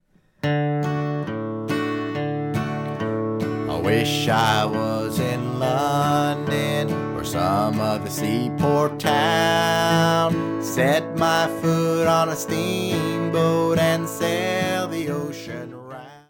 Guitar & voice, medium speed (key of D)